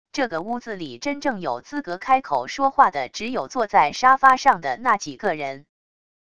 这个屋子里真正有资格开口说话的只有坐在沙发上的那几个人wav音频生成系统WAV Audio Player